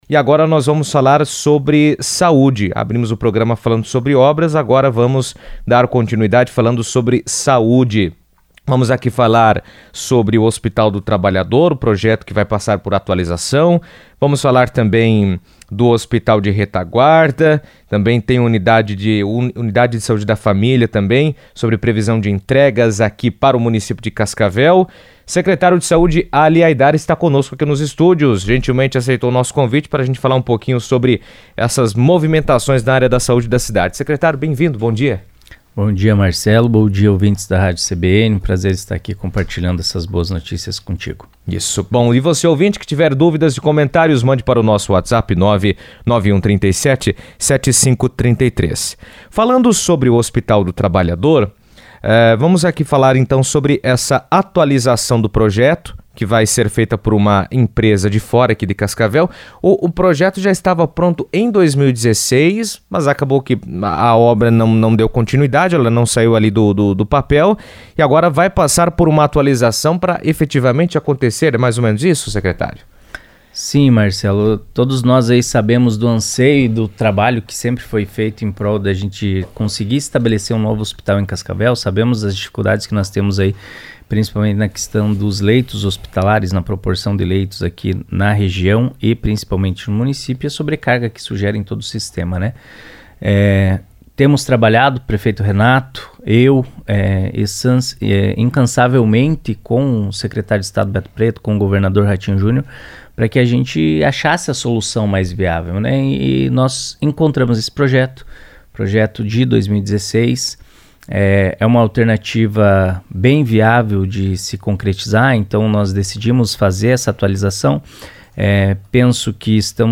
A Prefeitura de Cascavel contratou uma empresa especializada para atualizar o projeto do Hospital do Trabalhador, originalmente elaborado em 2016. Em entrevista à CBN, Ali Haidar, Secretário Municipal de Saúde, destacou que a atualização é necessária para adequar as normas sanitárias e estruturais, garantindo maior eficiência e segurança no atendimento. O projeto revisado deve detalhar novas instalações, fluxos de atendimento e adaptações que permitirão obras futuras e melhorias na funcionalidade do hospital.